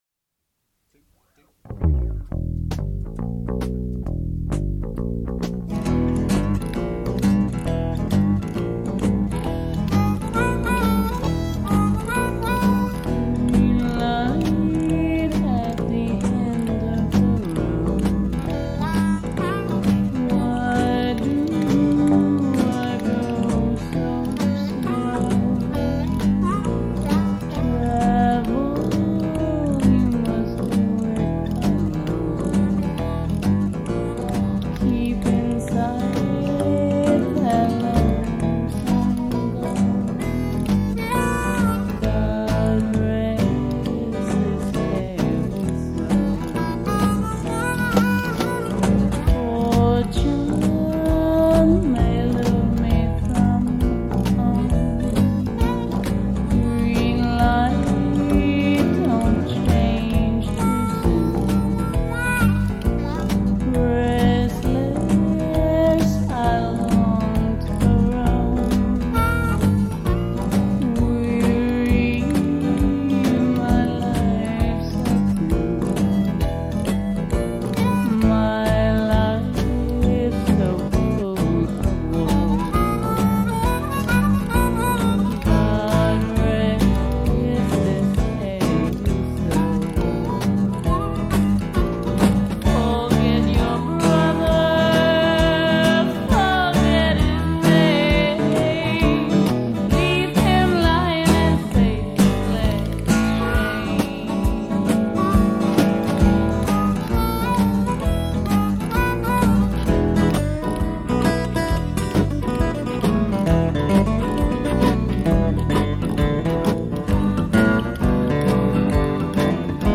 NY-based alt. country group fronted by two female singers.
harmonica
acoustic guitar
electric guitar
electric bass
drums